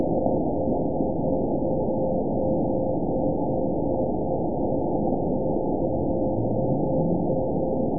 event 920330 date 03/16/24 time 20:28:52 GMT (1 year, 1 month ago) score 9.02 location TSS-AB02 detected by nrw target species NRW annotations +NRW Spectrogram: Frequency (kHz) vs. Time (s) audio not available .wav